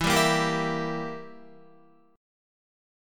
Edim chord